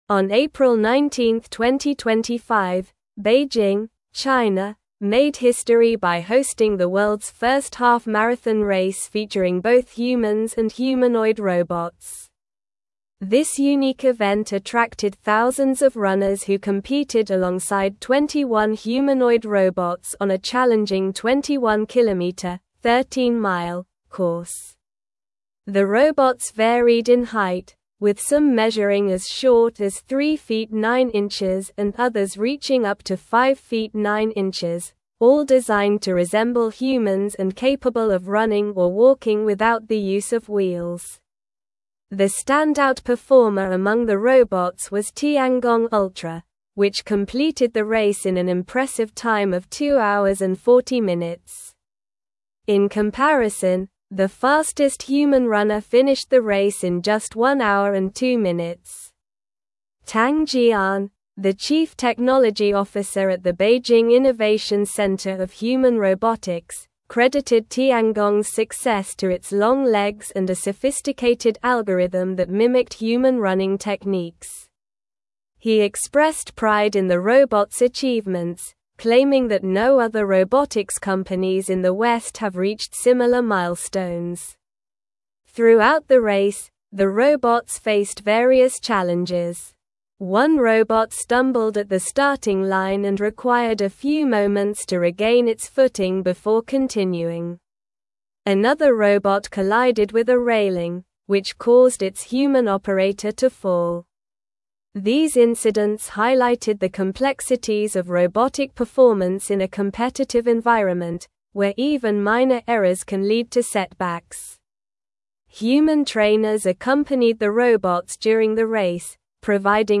Slow
English-Newsroom-Advanced-SLOW-Reading-China-Hosts-Historic-Half-Marathon-with-Humans-and-Robots.mp3